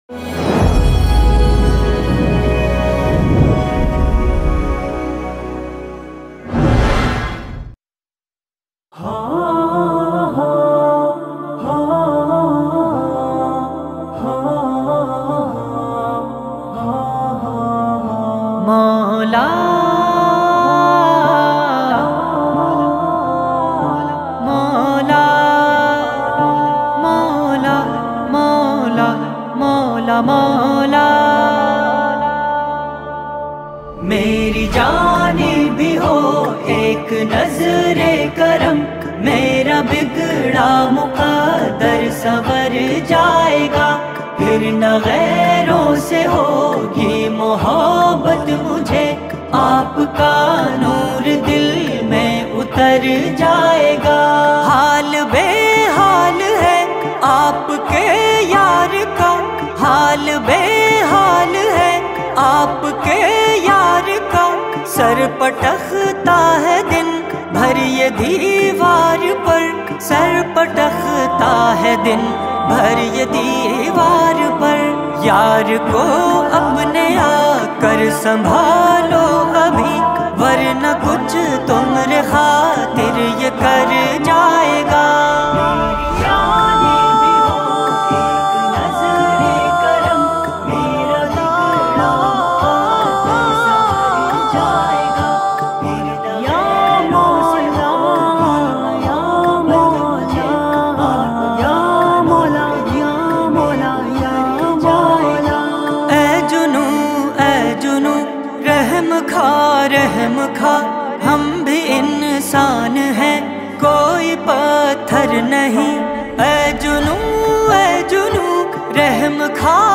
Naats